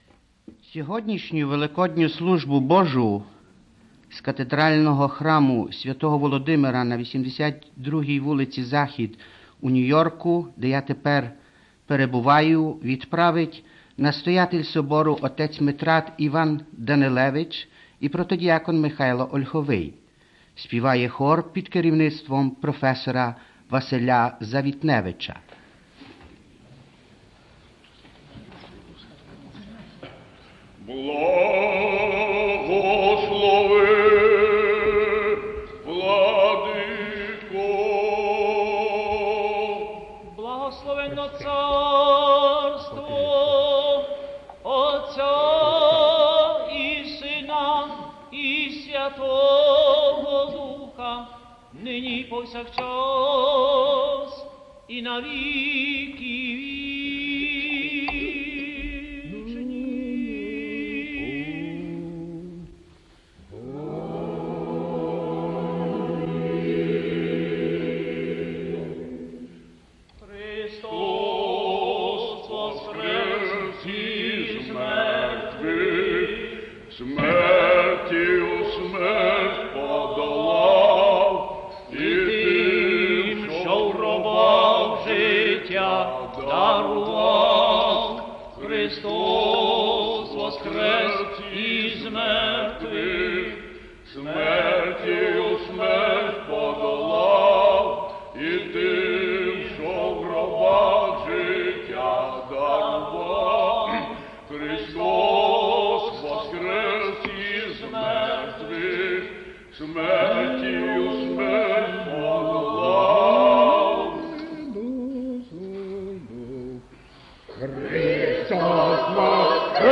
Gospel reading
Sermon and greeting of listeners in Ukraine
Station announcement
Cherubic hymn